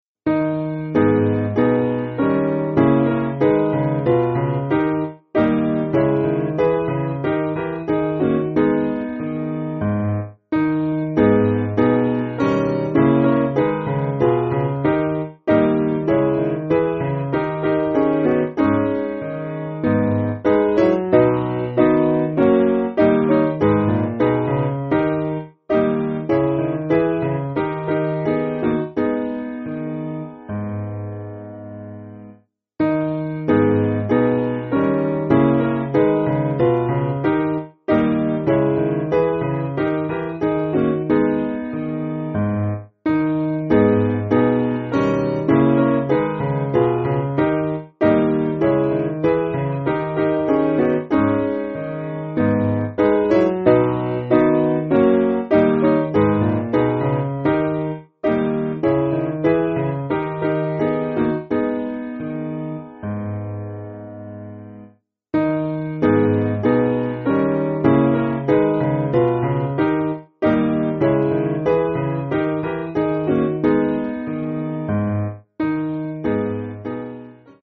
Simple Piano
(CM)   4/G#m